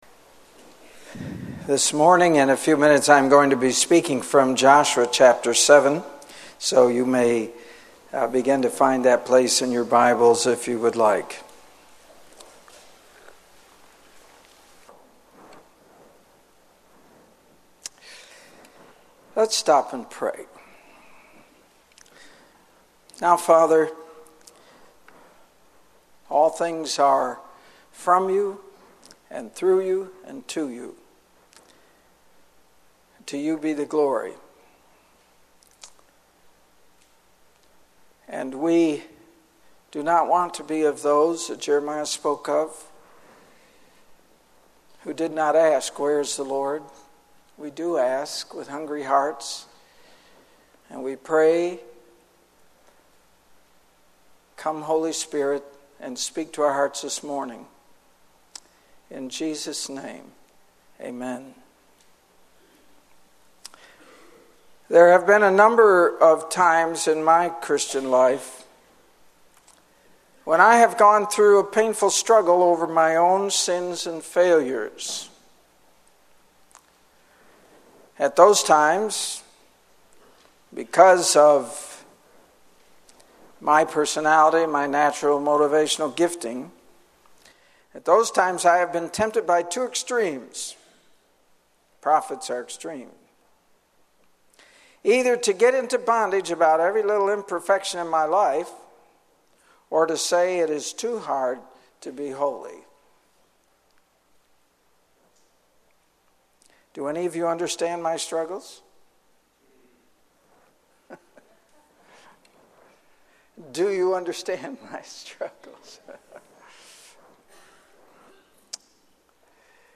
In this sermon, the preacher emphasizes the importance of dealing thoroughly with the things in our lives that God is leading us to address. He uses the story of the Israelites' disobedience in taking spoils from the city of Jericho as an example.